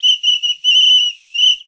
Soccer Match End.wav